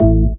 VES2 Synth Shots
VES2 Synth Shot 058 - A.wav